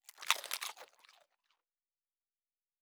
Food Eat 04.wav